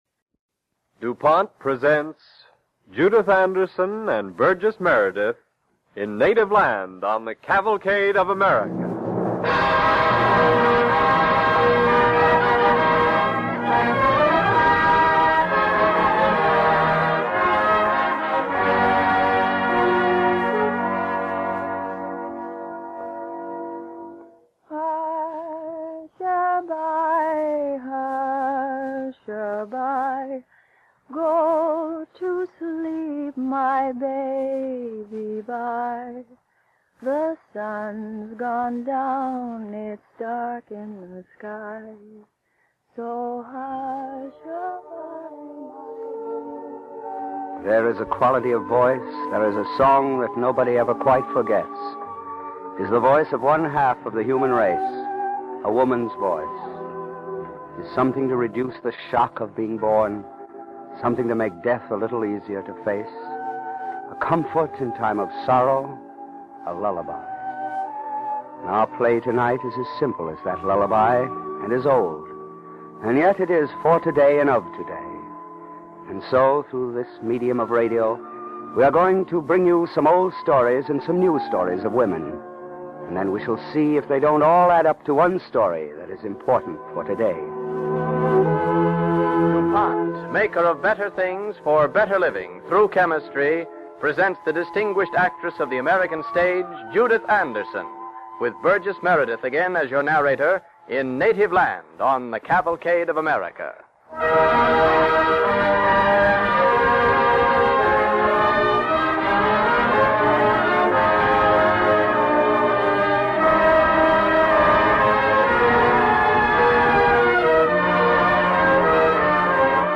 Cavalcade of America Radio Program
Native Land part 2, starring Burgess Meredith